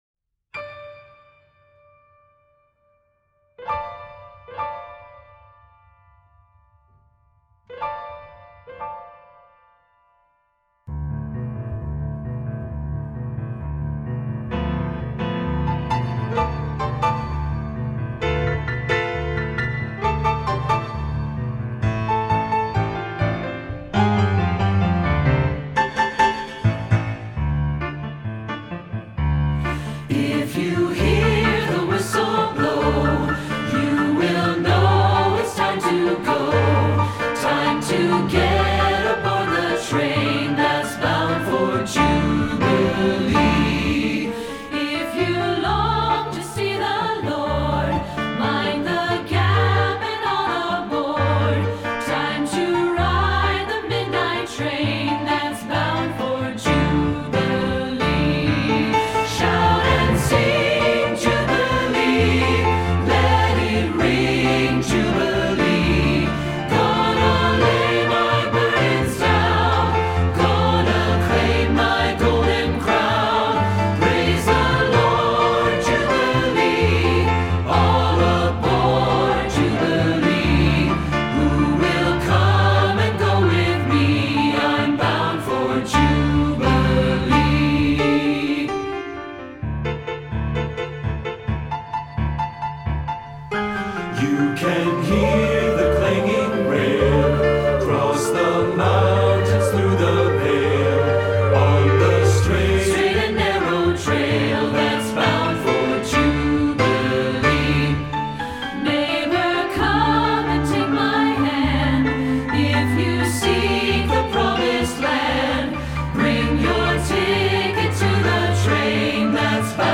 Choral Church
SATB